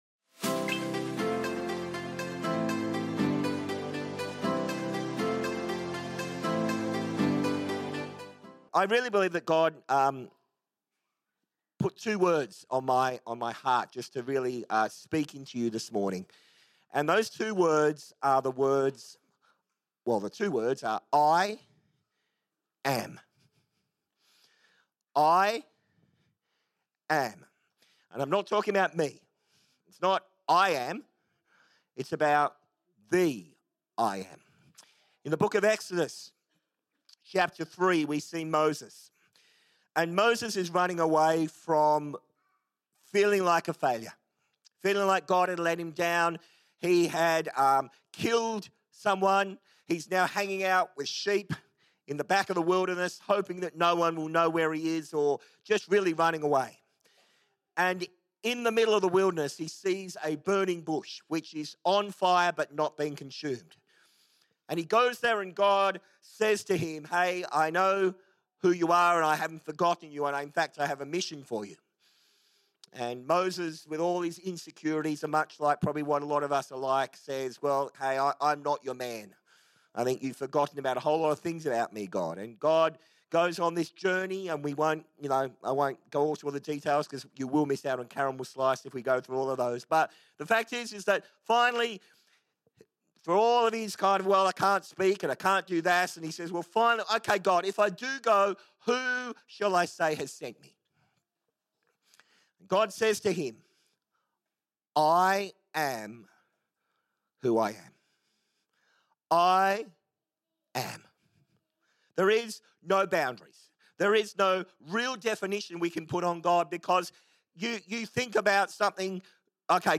This week our guest preacher